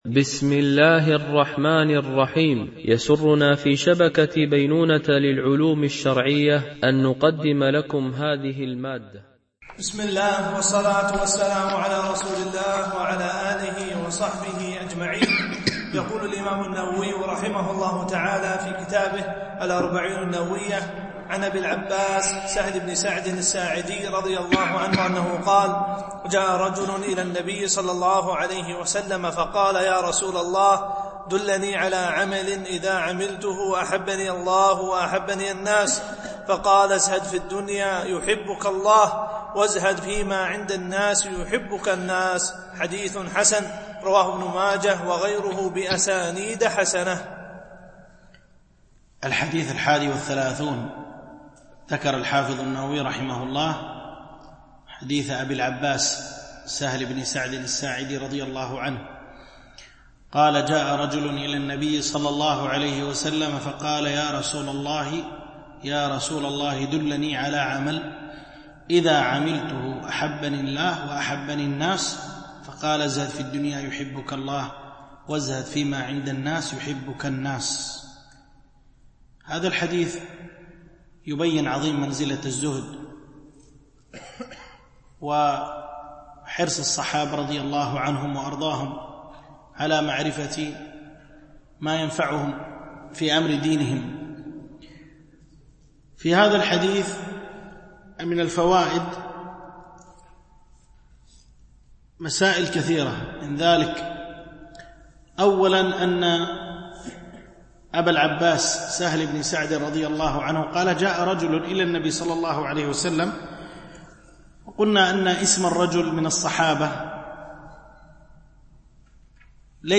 شرح الأربعين النووية - الدرس 16 (الحديث 30 - 31)
التنسيق: MP3 Mono 22kHz 32Kbps (CBR)